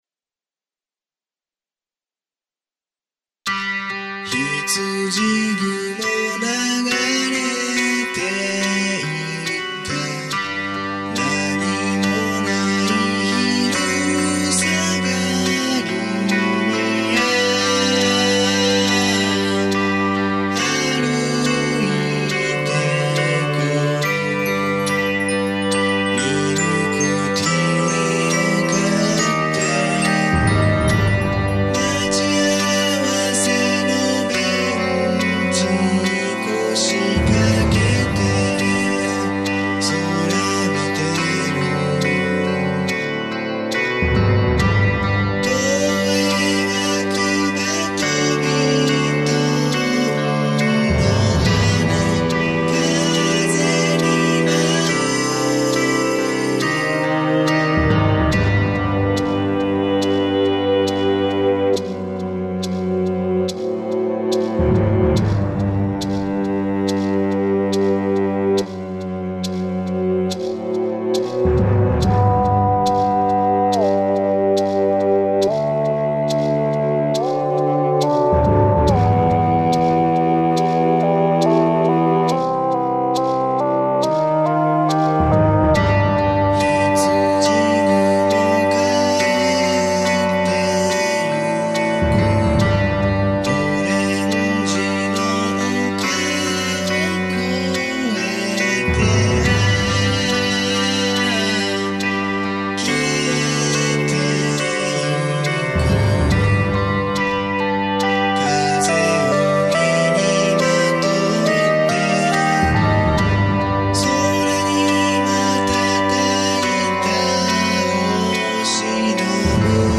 【ジャンル】 pop
【コメント】 短くてシンプルな曲です。